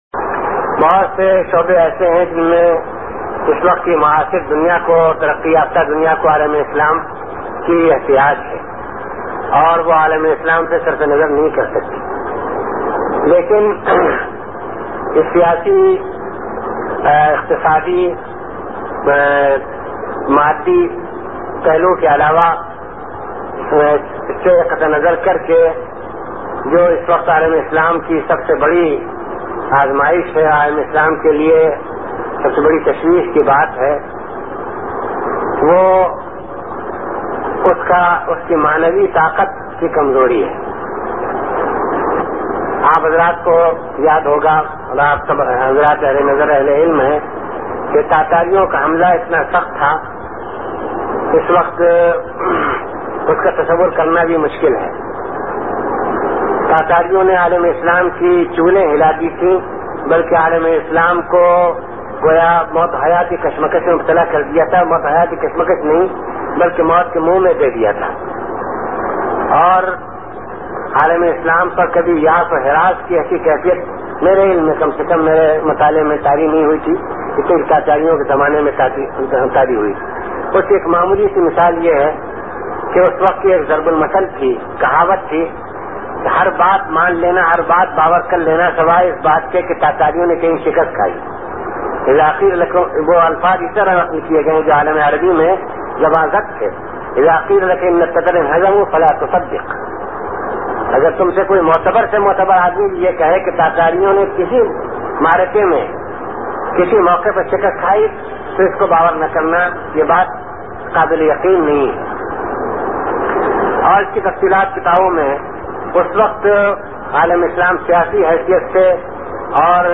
Speech 1983 Karachi